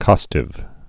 (kŏstĭv)